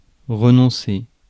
Ääntäminen
IPA: [ʁə.nɔ̃.se]